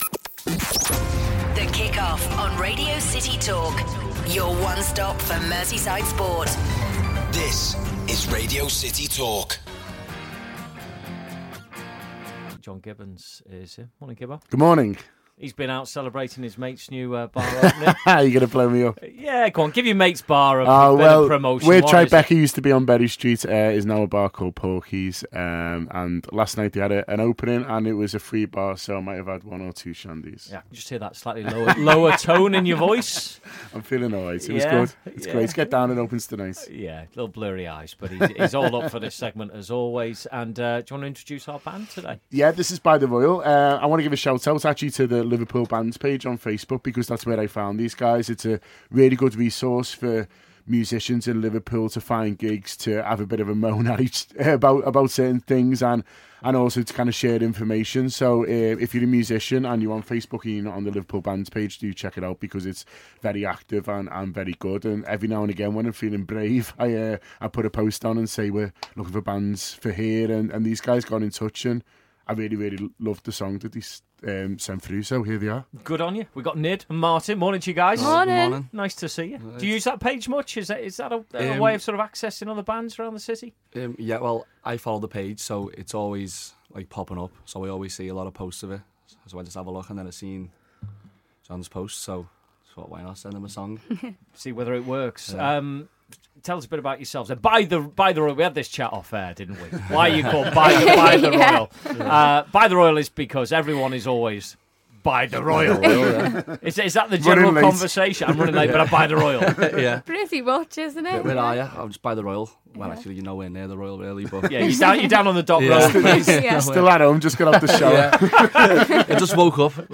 Brilliant local music